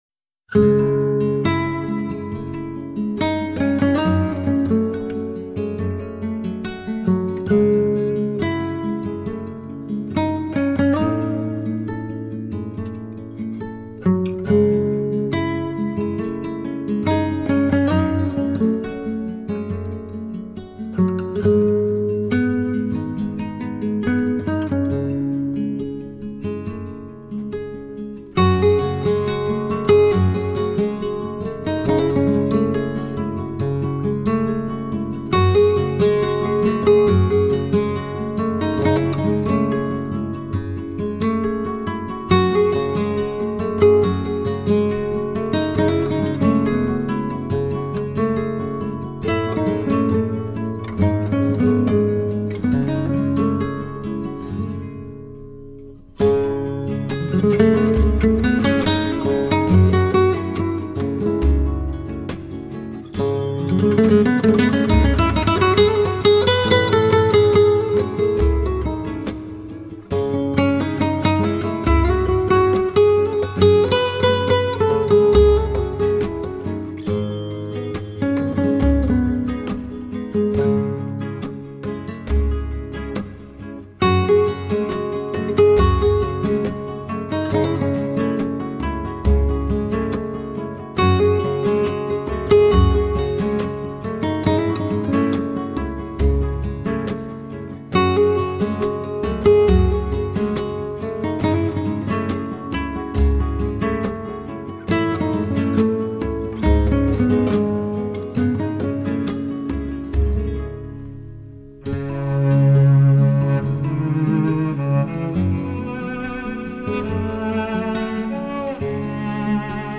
令人沉醉的新弗拉门哥吉他合辑